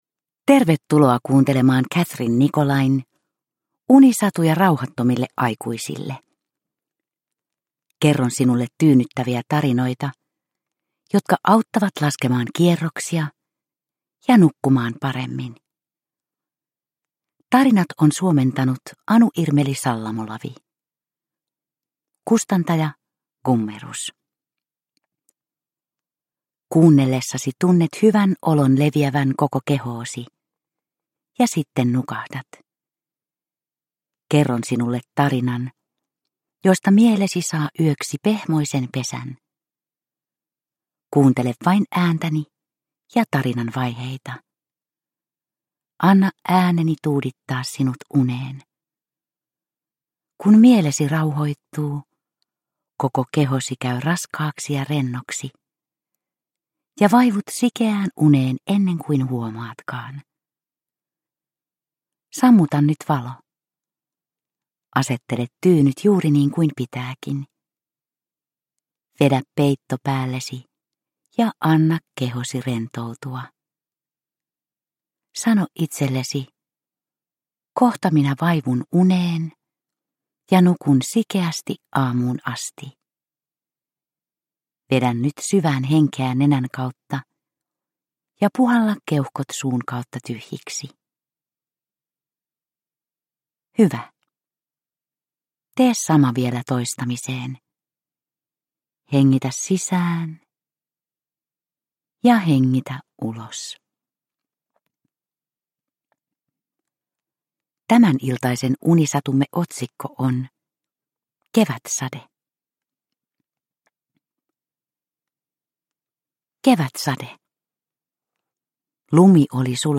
Unisatuja rauhattomille aikuisille 23 - Kevätsade – Ljudbok – Laddas ner